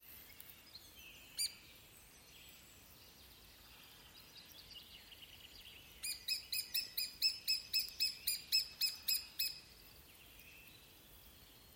Mazais dzenis, Dryobates minor
StatussDzied ligzdošanai piemērotā biotopā (D)